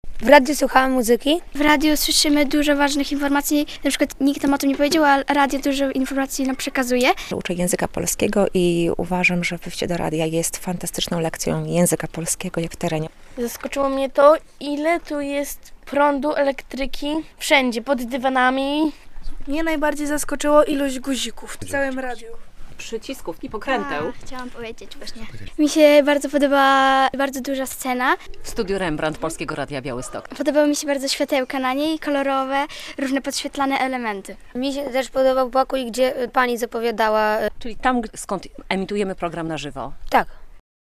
Dzień otwarty - relacja